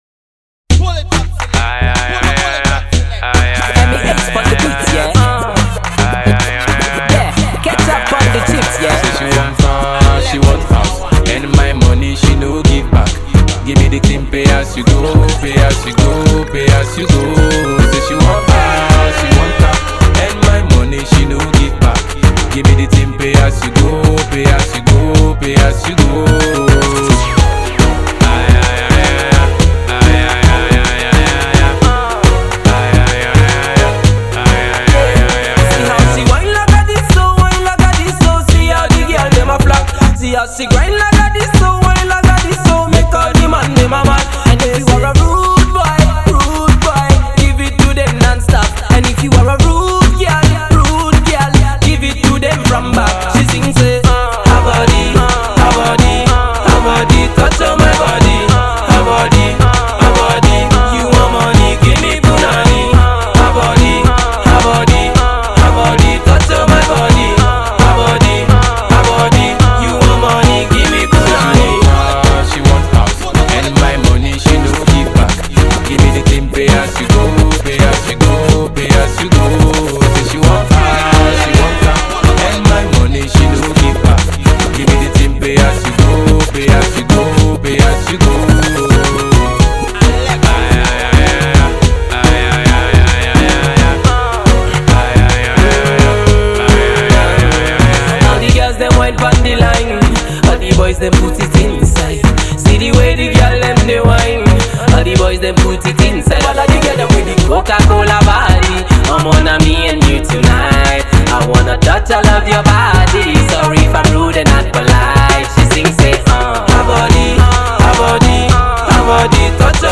the Dancehall/Afro pop sensation
catchy tunes